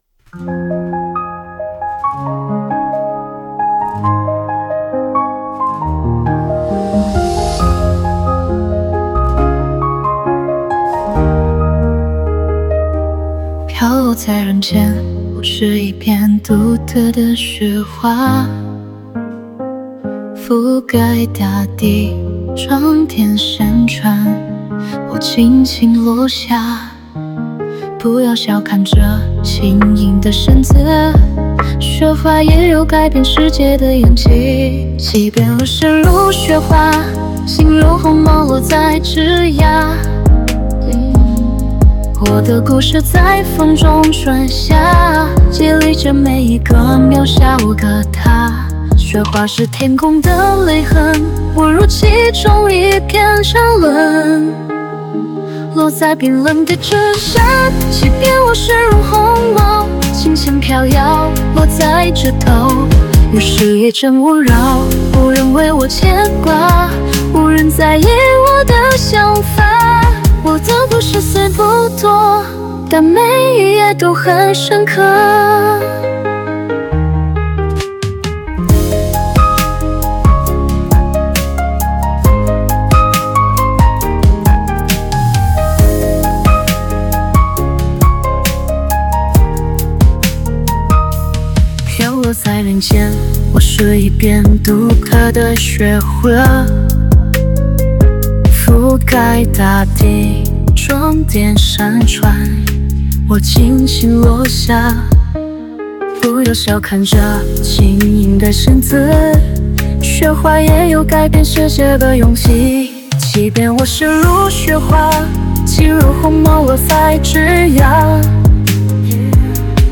在线试听为压缩音质节选